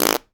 fart_squirt_13.wav